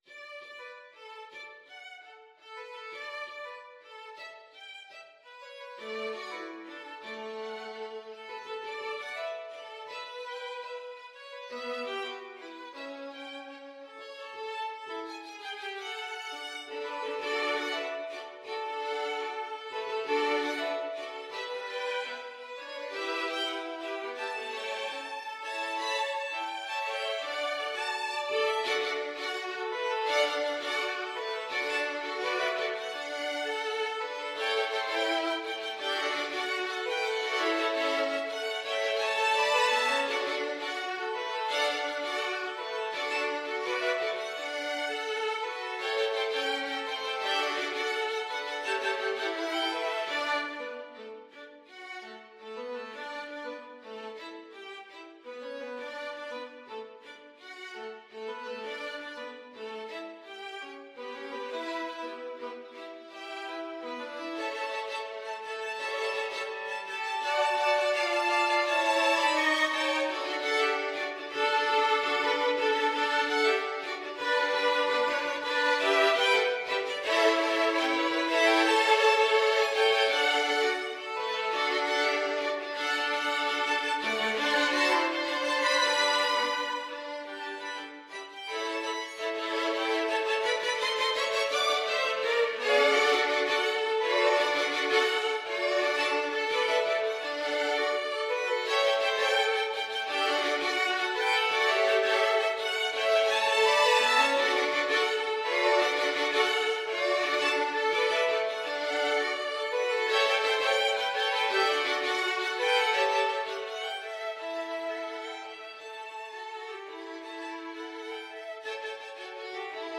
Molto Allegro =c.168 (View more music marked Allegro)
4/4 (View more 4/4 Music)
Christmas (View more Christmas Violin Quartet Music)